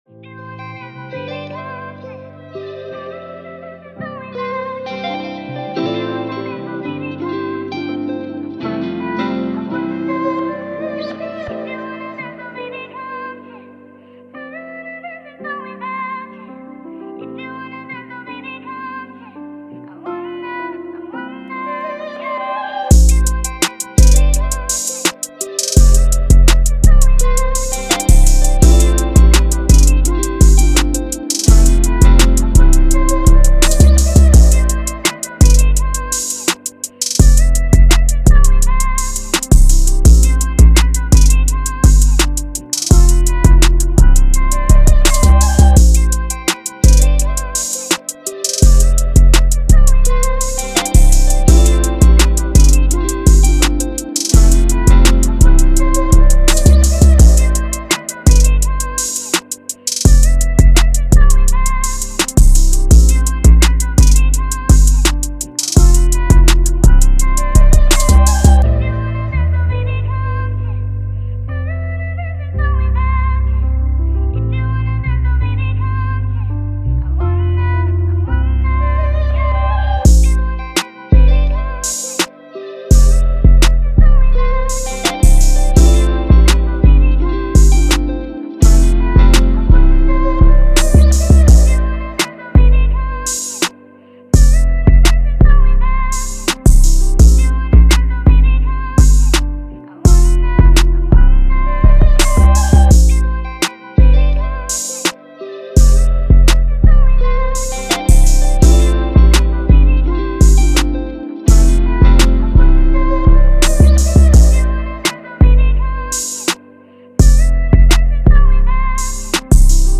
features a catchy guitar riff and a soulful melody
With its emotive melody and memorable hook